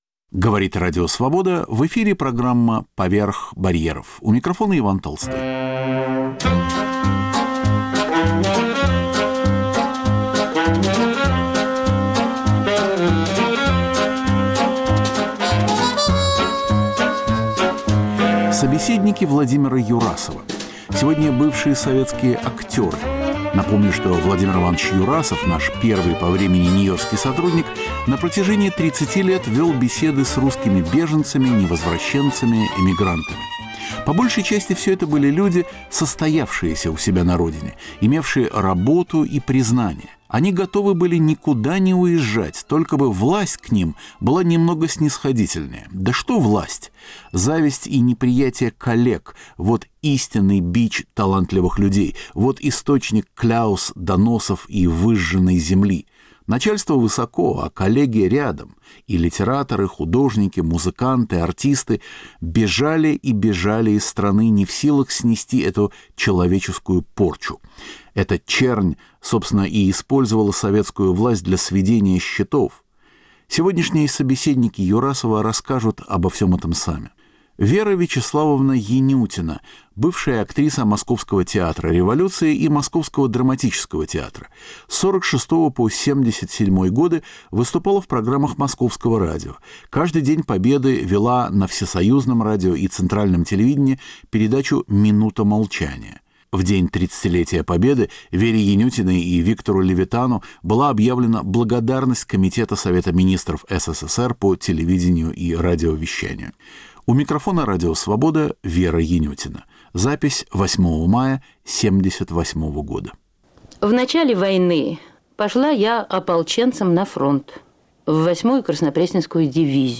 Несколько десятков человек прошло в 60-70-е годы через Нью-йоркскую студию Свободы. Мы стараемся подбирать архивные записи тематически.